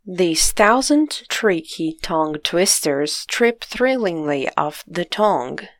Mas não se preocupe: os áudios para que você escute as pronúncias corretas e pratique todos eles estão presentes para te ajudar, como sempre!
Veja que ele mistura sons que vimos hoje.